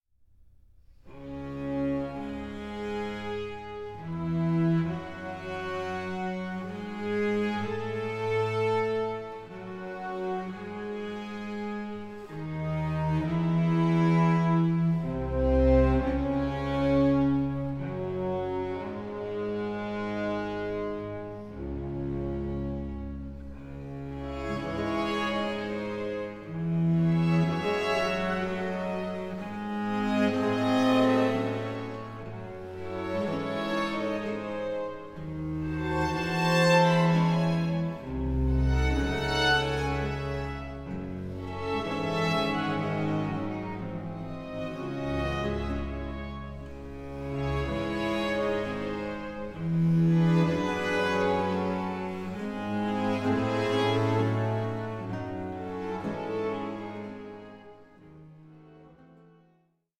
CREATIVE INTERPRETATIONS OF BAROQUE MONUMENTS